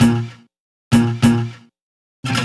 Hands Up - Chopped Strum.wav